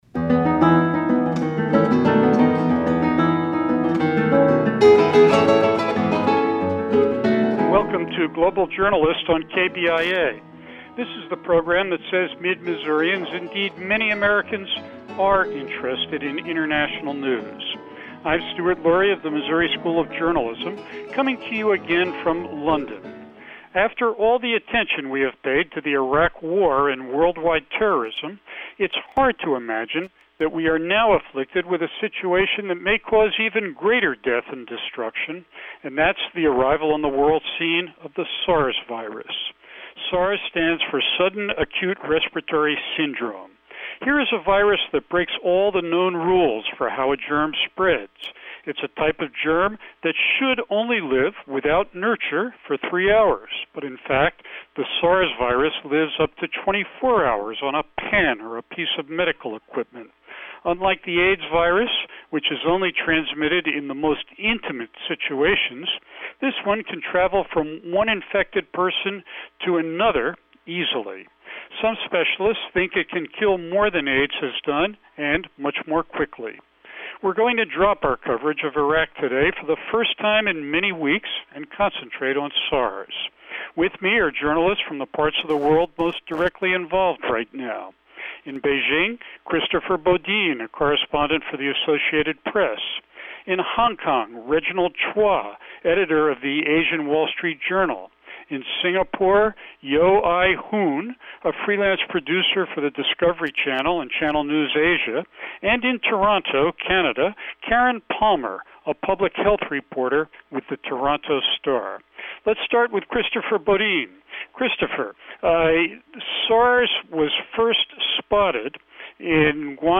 This April 24, 2003 interview on the Global Journalist features journalists based in Beijing, Hong Kong, Singapore and Toronto discussing the viral outbreak of SARS, sudden acute respiratory syndrome.